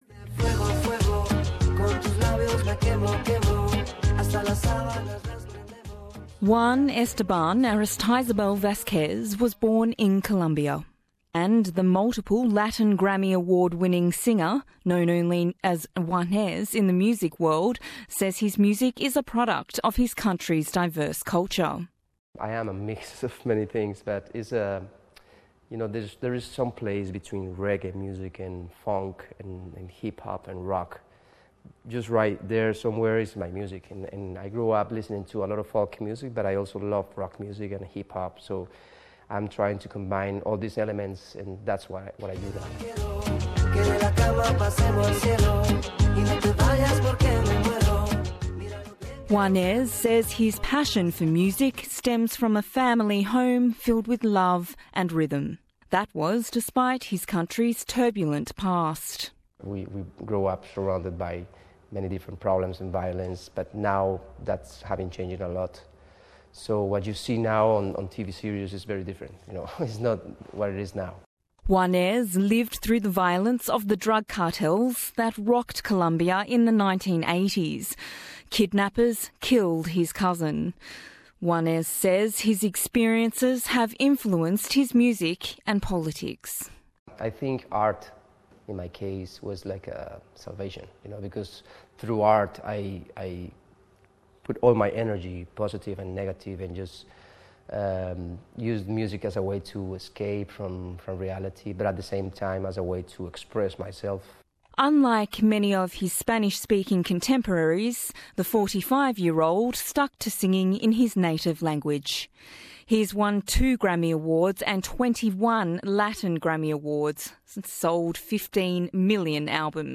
The Colombian singer Juanes, performing in Australia, has spoken with SBS about his country's diversity, its violent past and how it all led him to Grammy success.